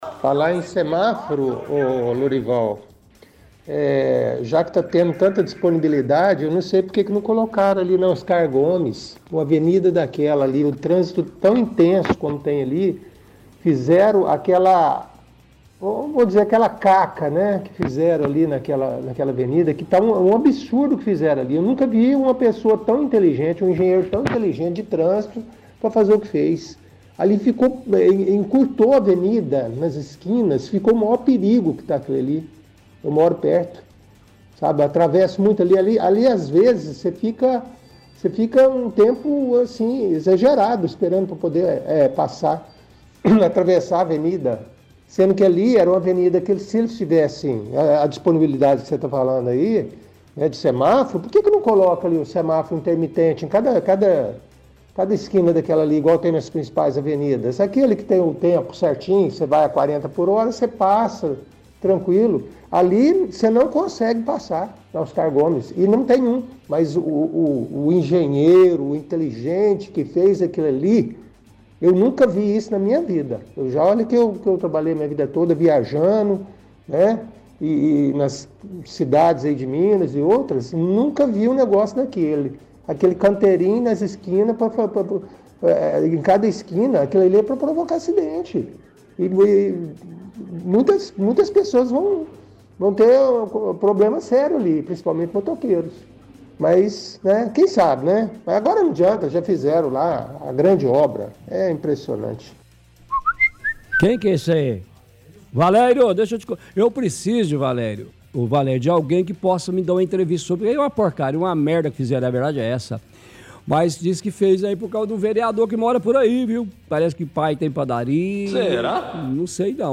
– Ouvinte reclama que não colocaram semáforos na avenida Oscar Gomes, diz que encurtaram a avenida por conta de canteiros no meio da pista, fala que objetivo da prefeitura é causar acidentes.